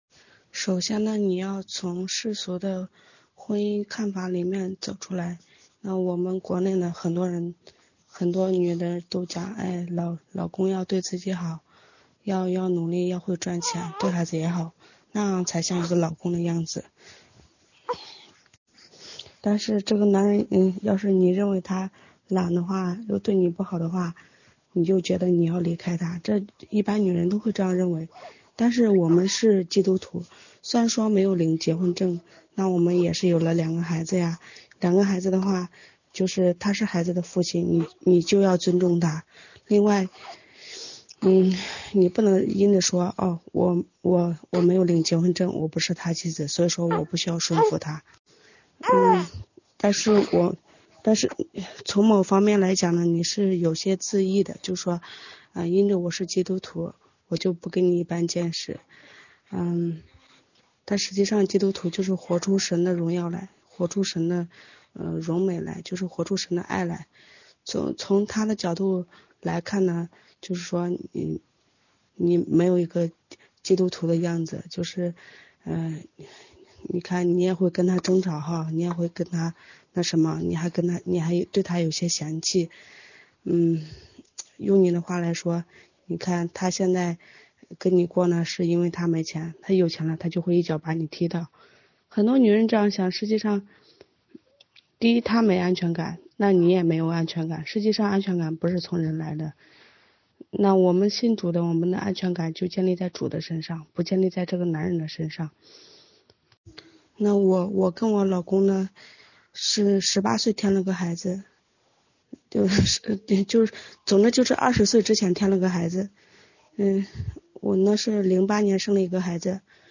姐妹见证-凡事让主高兴-.mp3